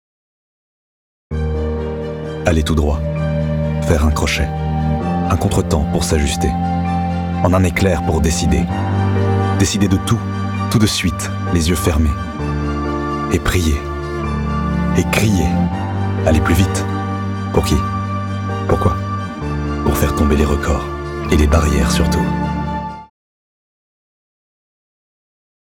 15 - 60 ans - Baryton